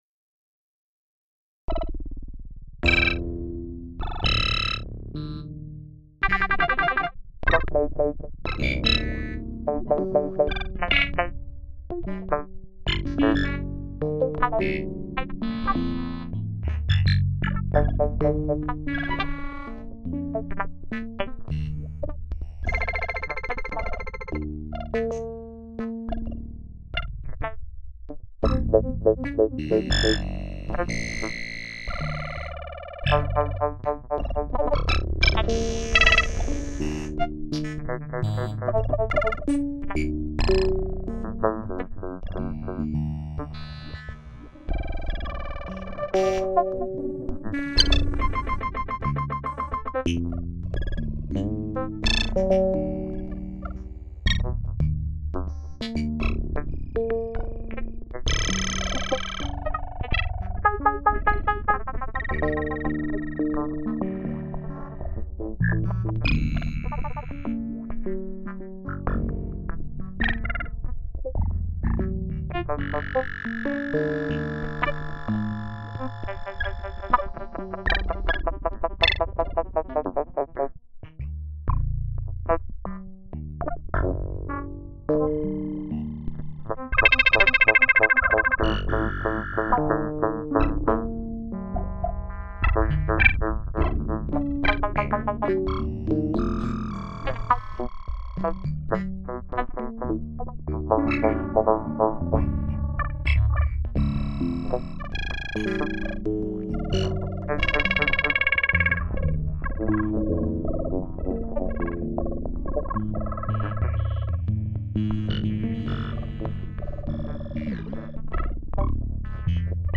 Solo Improvisations